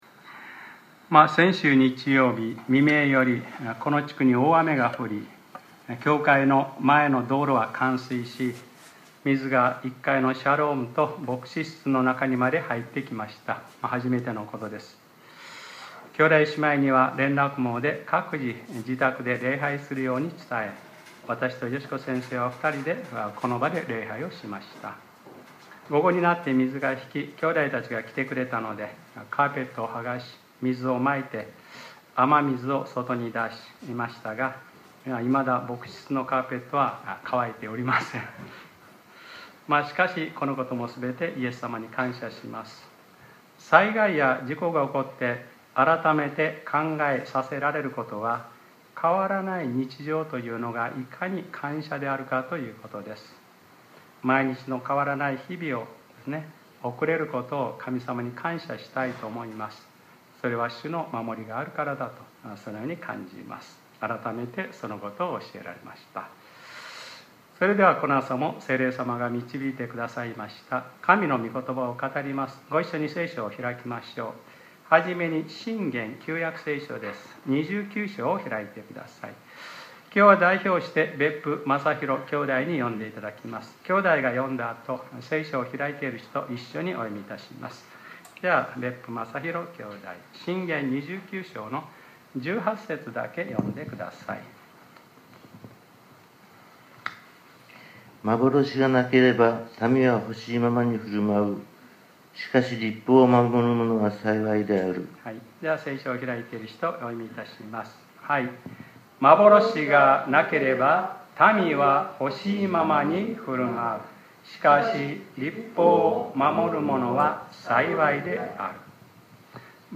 2019年07月28日（日）礼拝説教『幻がなければ』